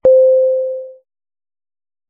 dong.mp3